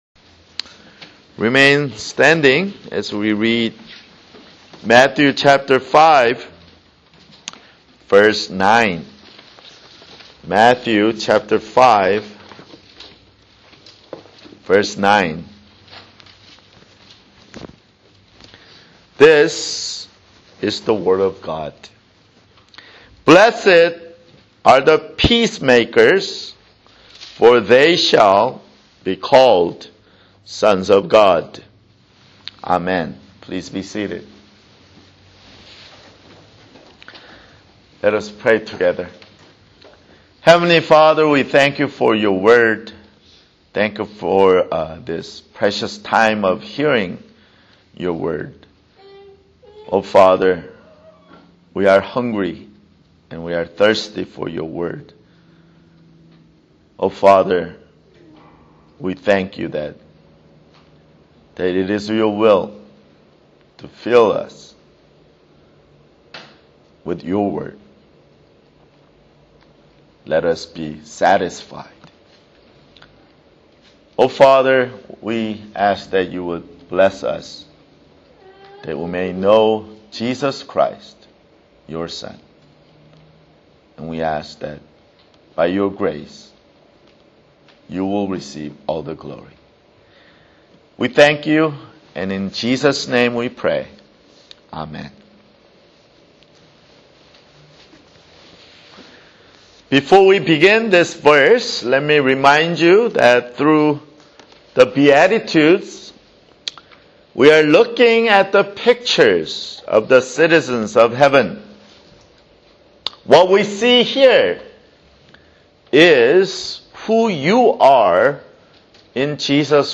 [Sermon] Matthew (20)